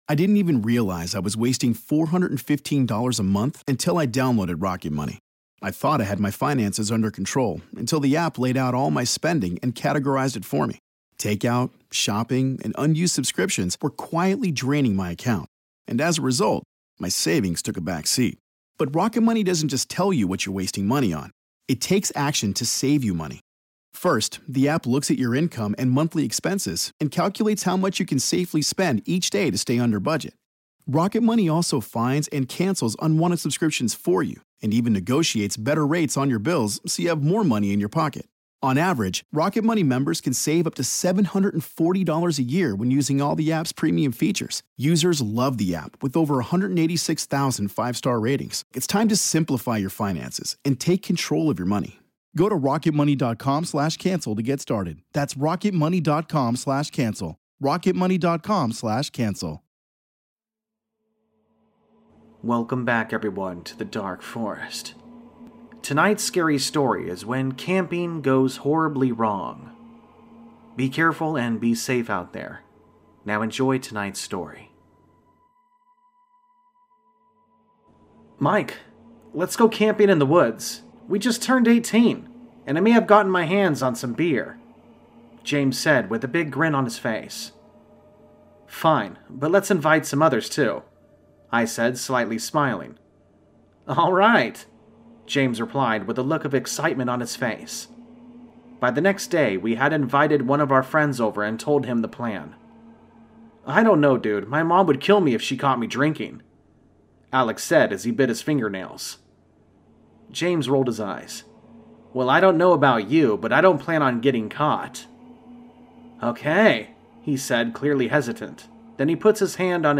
All Stories are read with full permission from the authors: